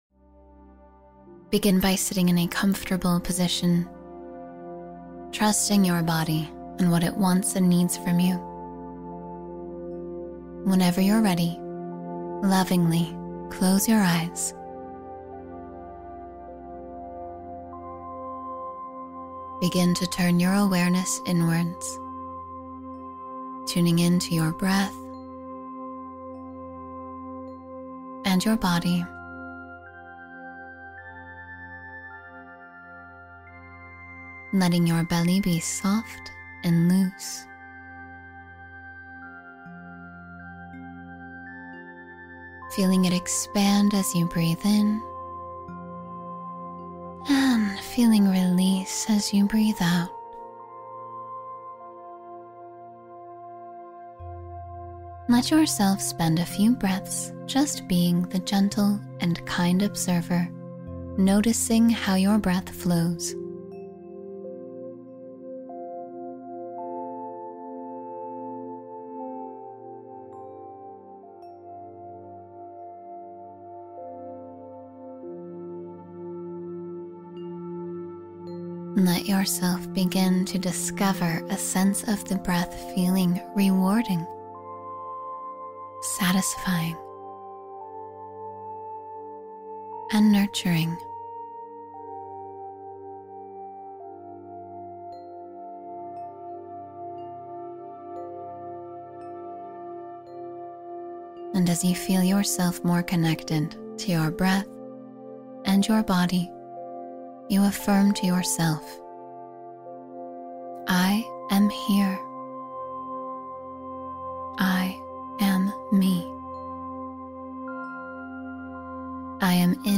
Deep Relaxation for Peace and Renewal — Guided Meditation for Stress Relief